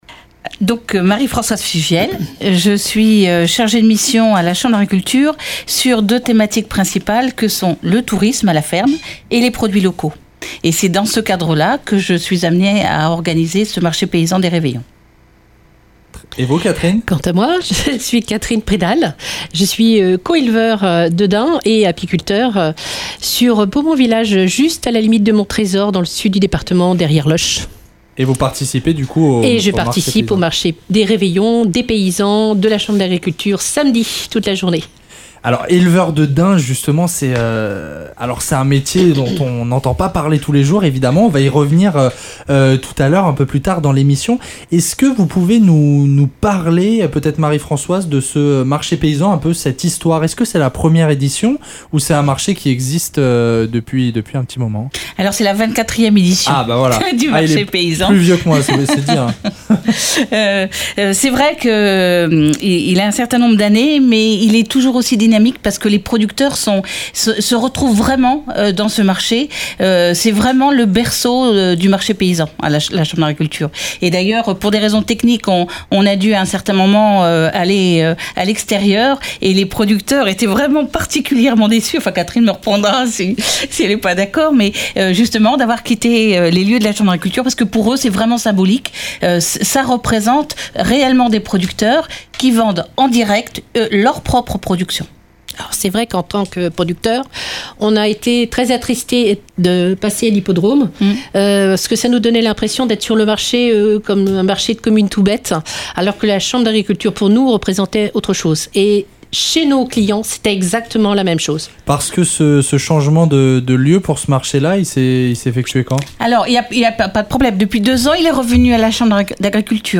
Un débat autour du marché paysan qui aura lieu samedi 23 décembre à Chambray-lès-Tours. Interview !